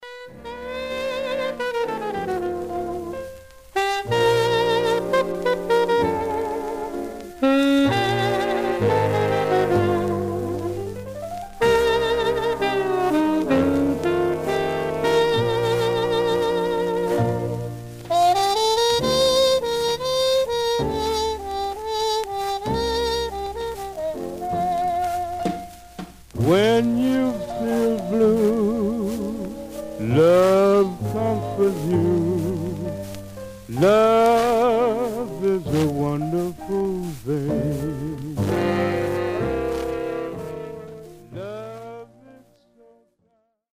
Stereo/mono Mono
Jazz